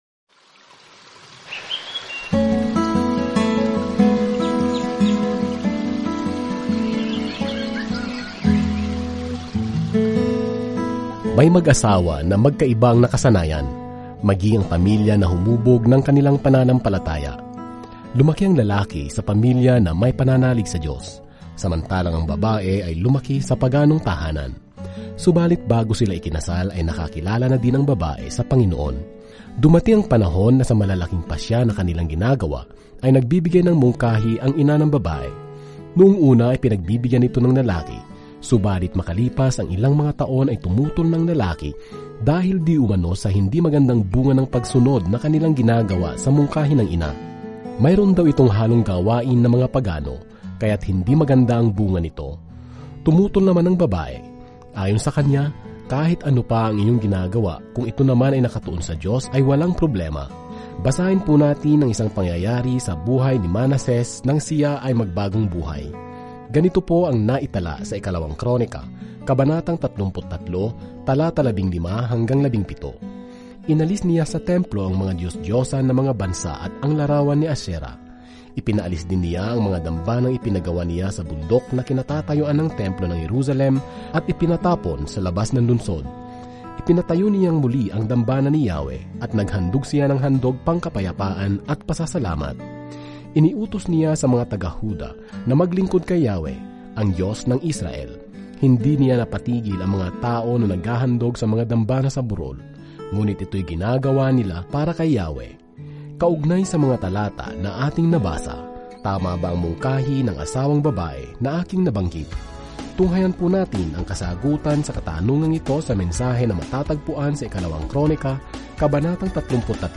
Banal na Kasulatan 2 Mga Cronica 33 Araw 16 Umpisahan ang Gabay na Ito Araw 18 Tungkol sa Gabay na ito Sa 2 Cronica, nakakuha tayo ng ibang pananaw sa mga kuwentong narinig natin tungkol sa mga nakaraang hari at propeta ng Israel. Araw-araw na paglalakbay sa 2 Cronica habang nakikinig ka sa audio study at nagbabasa ng mga piling talata mula sa salita ng Diyos.